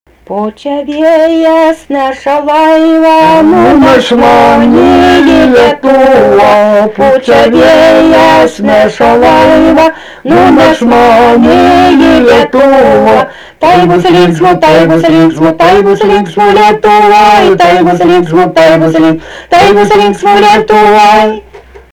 Dalykas, tema daina
Erdvinė aprėptis Juciai
Atlikimo pubūdis vokalinis
Pastabos 2 balsai